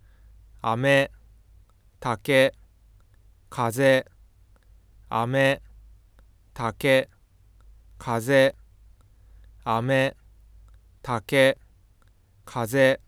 上の図は，1番目の単語が「飴」，2番目が「竹」，3番目が「風」です。いずれも平板型で発音されており，また第1モーラの母音が/a/，第2モーラが/e/という点も共通しています。
「飴」ではfoが途切れないのに対し，「竹」では明らかに途切れています。
ところで，「竹」の終端ではfoが急上昇しているように見えますが，foがこのように急激に変動することは生理的に考えにくく，また音声を聞いた印象としても上昇しているようには聞こえないので，ソフトウェアによるfoの誤検出であると考えられます。